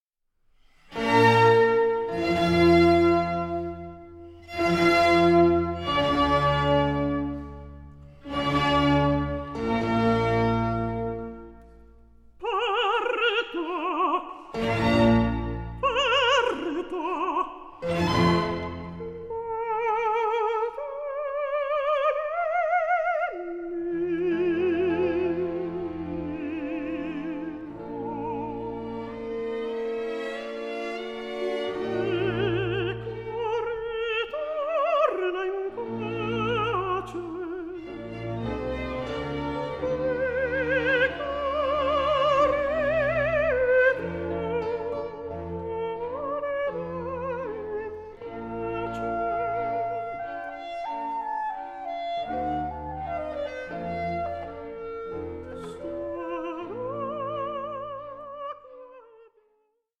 Counter-tenor
the recorded works share a sublime and profound character